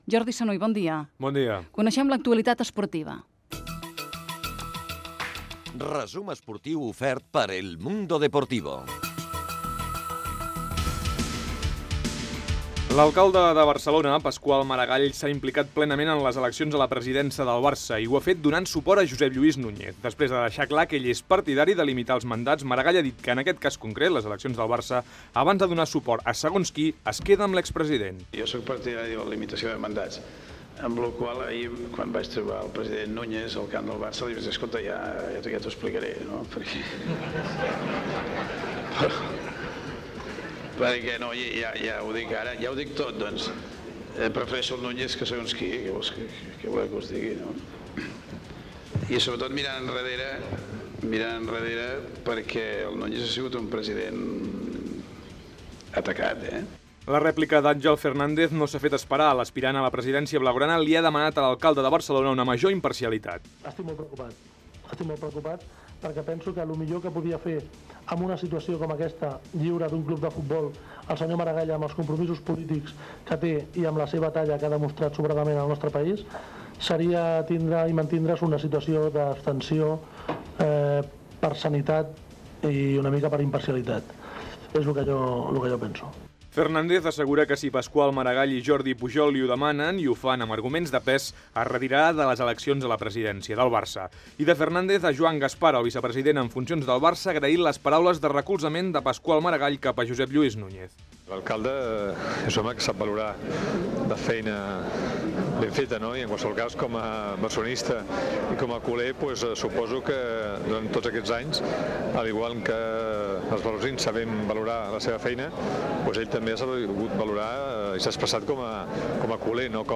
Publicitat, resum esportiu de l'any
Info-entreteniment
Programa presentat per Josep Cuní.